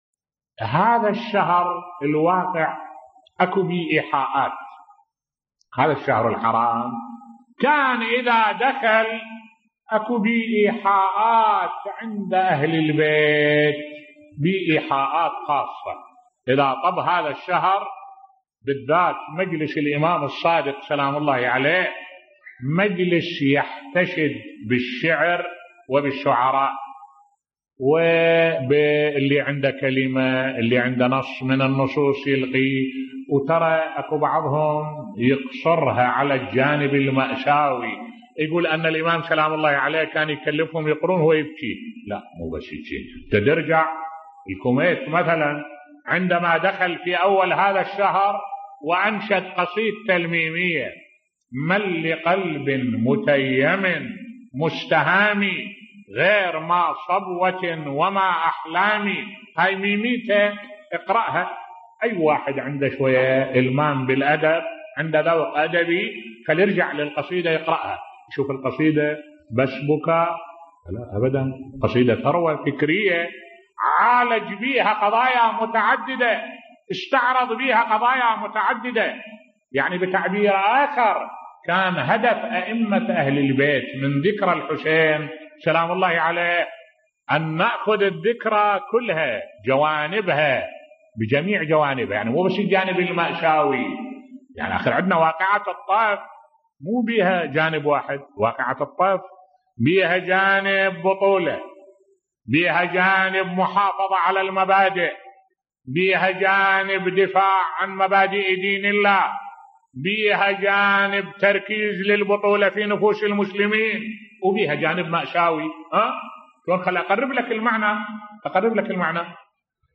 ملف صوتی احياء أئمة أهل البيت لعاشوراء لم يكن للعاطفة فقط بصوت الشيخ الدكتور أحمد الوائلي